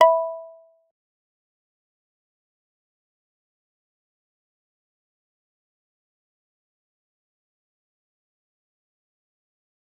G_Kalimba-E6-mf.wav